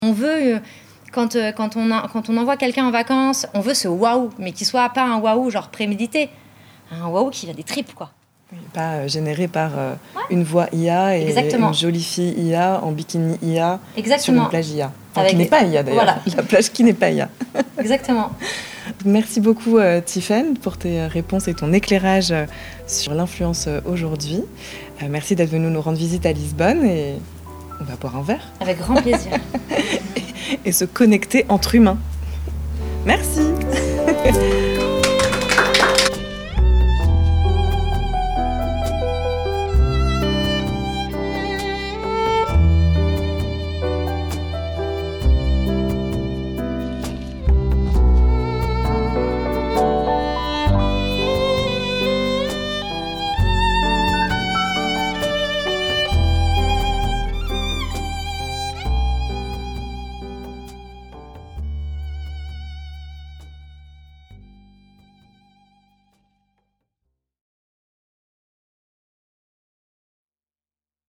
C’est lors du montage que ces digressions trouvent leur place, insufflant un rythme naturel à l’ensemble. Parallèlement, l’ajout d’un générique, d’une introduction et d’une conclusion journalistique vient structurer et encadrer l’entretien, tout en lui offrant une cohérence narrative.
Entretien : journaliste / interviewé
3 – Fin de l’entretien monté & mixé